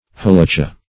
Halacha \Ha*la"cha\ (h[.a]*l[aum]"k[.a]), n.; pl. Halachoth
halacha.mp3